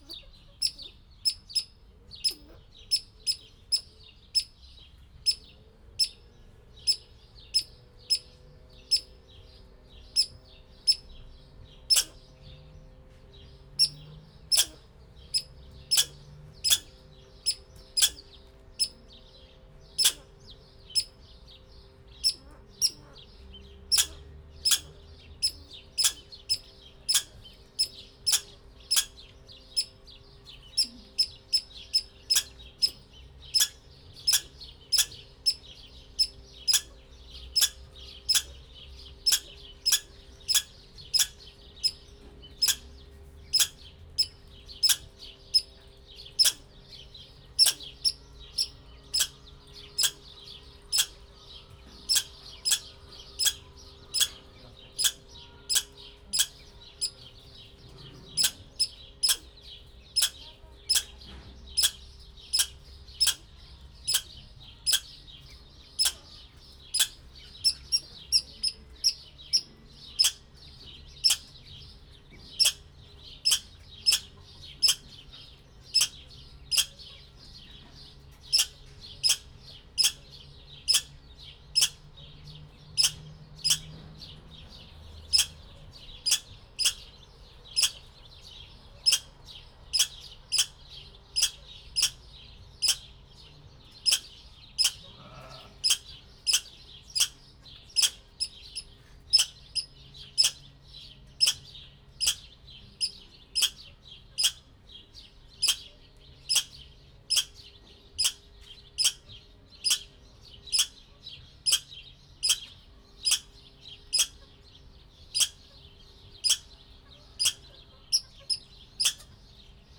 debrecenizoo2019_professzionalis/kanadai_voros_mokus